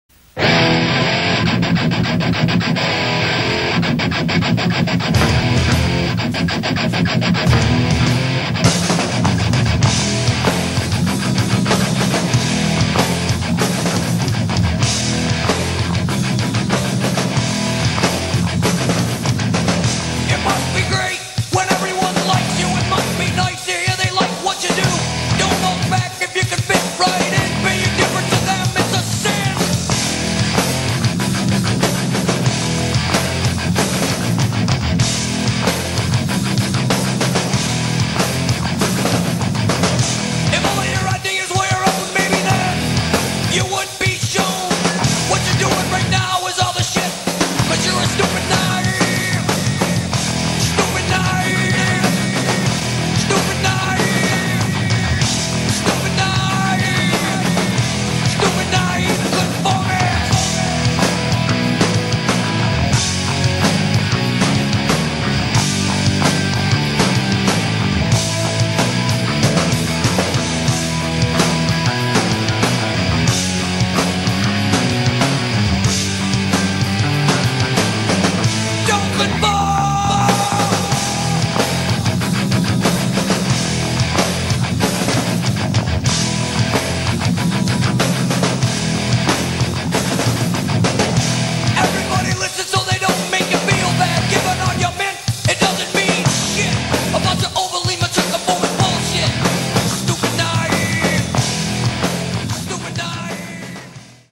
#90srockmusic